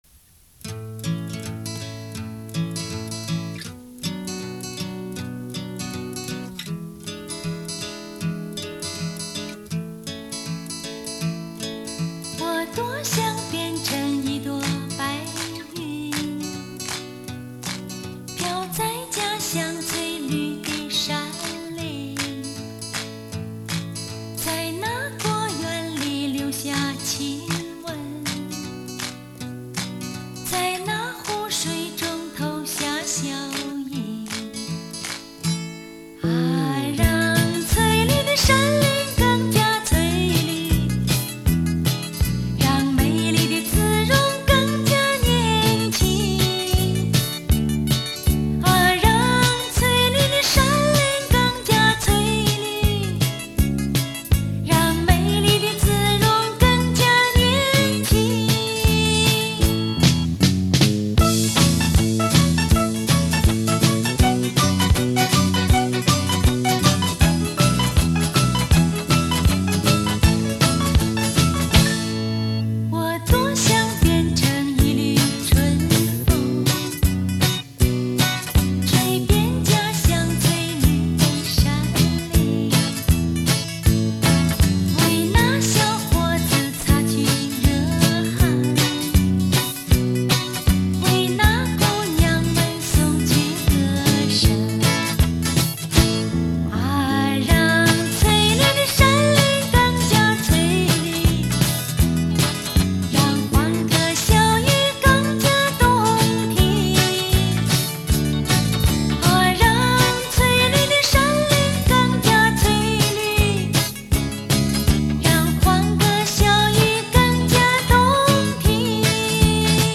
80年代女歌手
介质：磁带